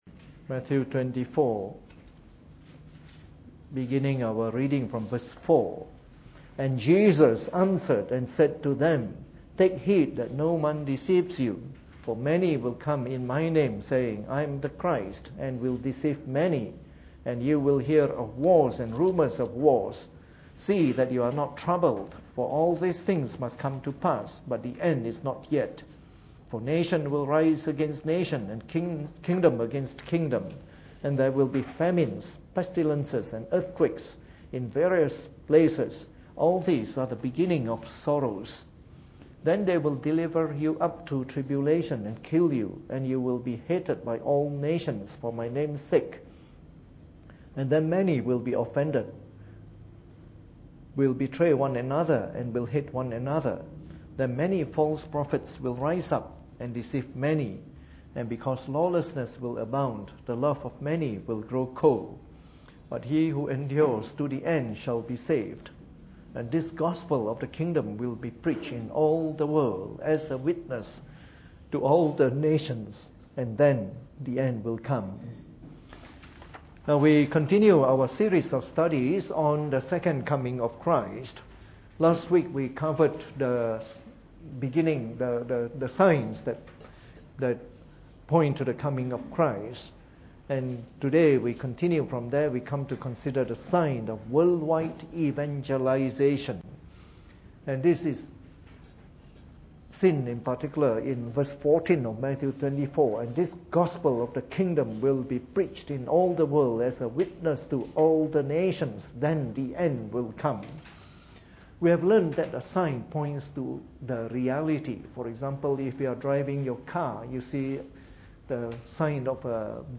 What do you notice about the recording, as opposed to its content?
Preached on the 15th of October 2014 during the Bible Study, from our series of talks on Eschatology.